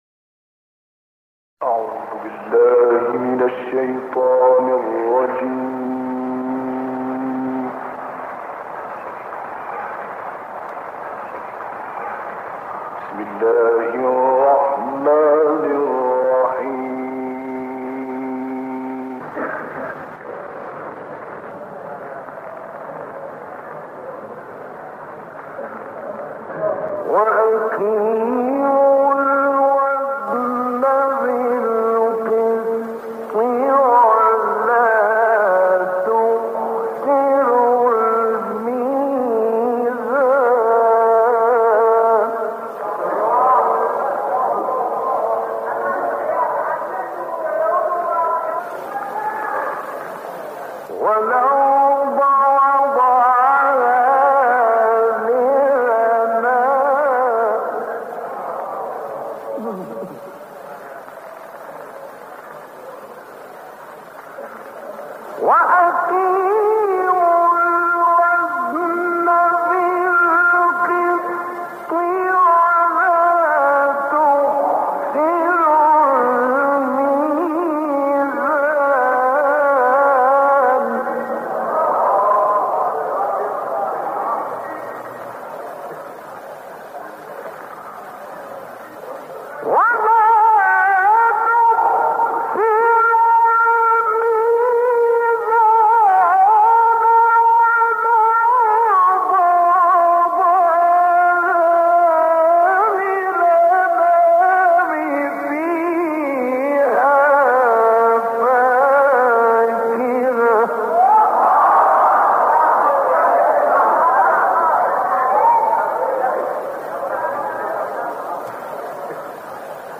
تلاوت سوره الرحمن با صدای غلوش + دانلود/ شناخت نعمت‌ها؛ نردبان معرفت‌الله
گروه فعالیت‌های قرآنی: تلاوتی زیبا از استاد مصطفى غلوش از آیات ۹ - ۳۳ سوره الرحمن و آیات ۱- ۲۴ سوره الحاقه ارائه می‌شود.